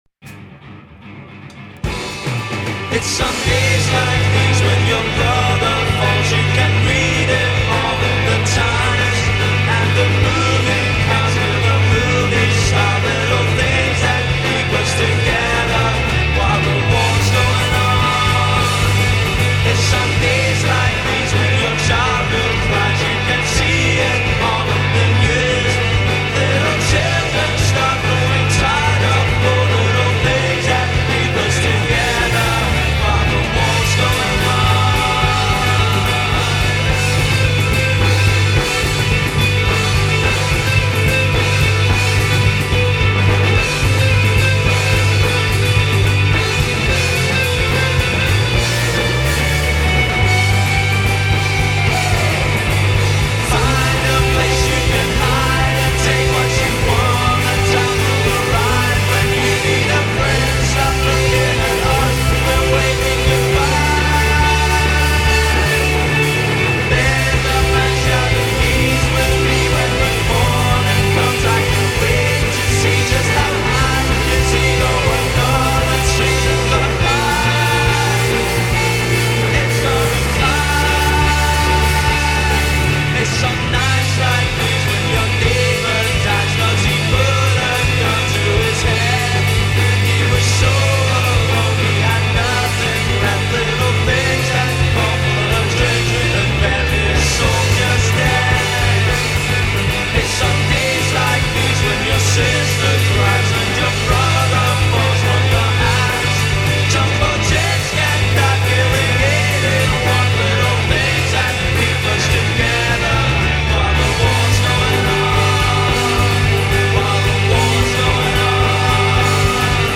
ramshackle beauty
post-punk
a breathless